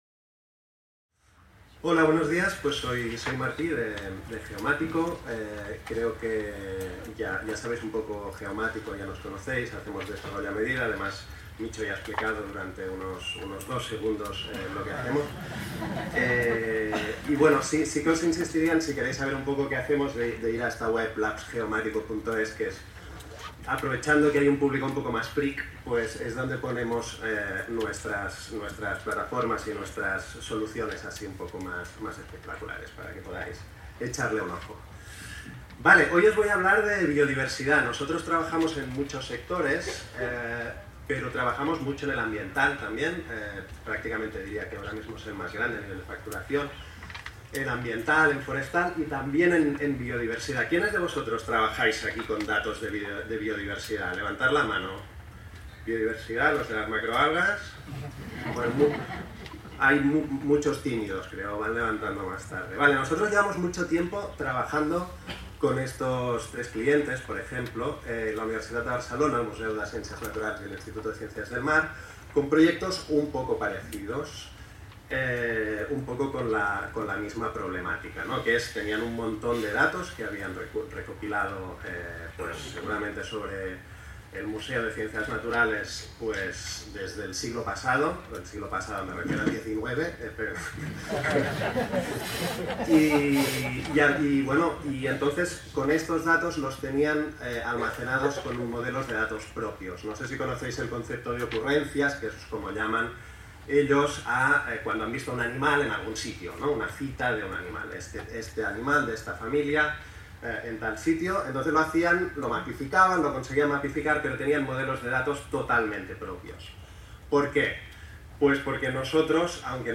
Presentació
en el marc de les "XVIII Jornadas SIG Libre, Geotech & Spatial Data Science" el dia 17 de setembre del 2025 sobre casos reals de modernització de portals de biodiversitat amb software lliure per a institucions com la Universitat de Barcelona o l'Institut de Ciències del Mar.